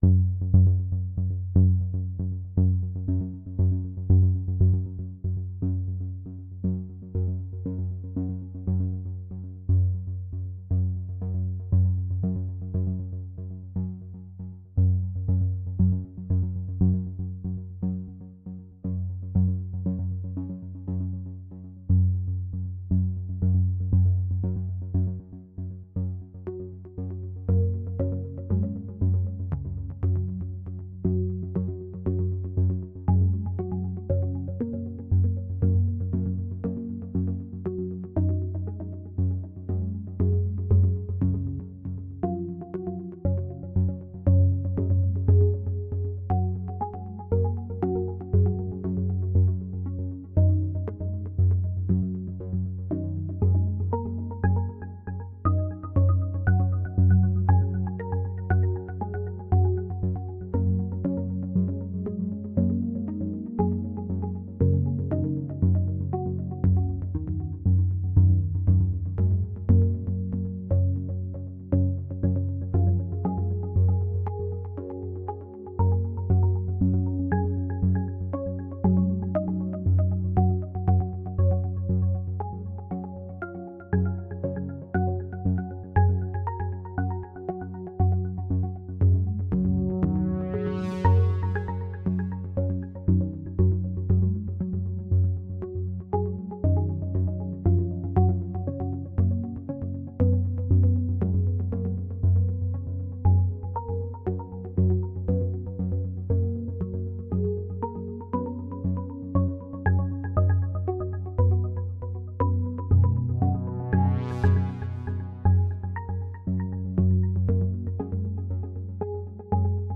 ohne Musik , screencast